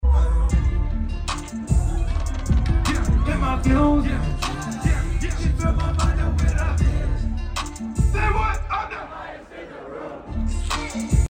reading festival